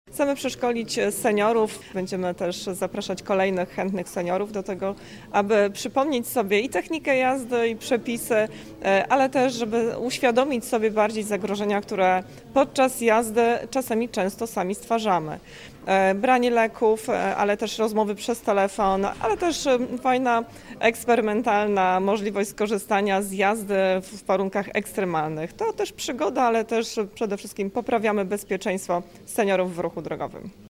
Bezpieczeństwo na drogach jest dla nas priorytetem – podkreśla Anna Brzezińska, członkini zarządu województwa mazowieckiego: